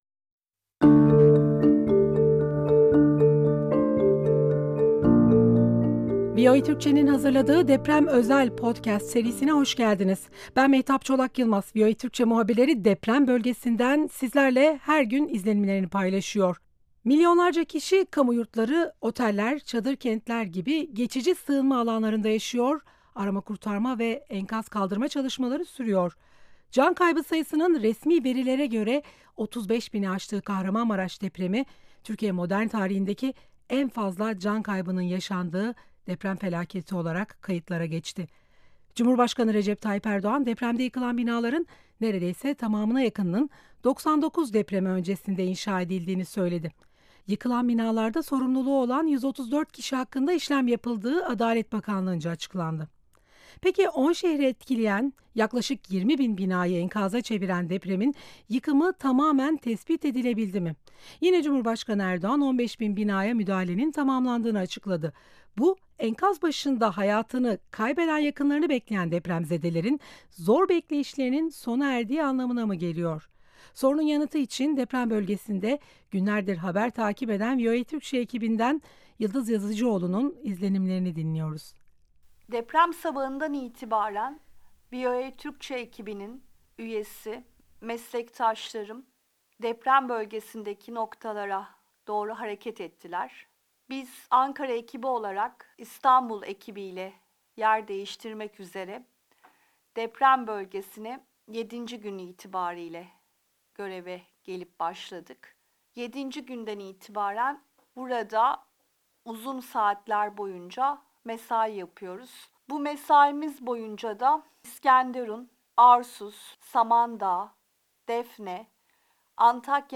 VOA Türkçe muhabirleri depremin yaşandığı ilk günden bu yana deprem bölgesinde.
son birkaç gündür Hatay'dan bildiriyor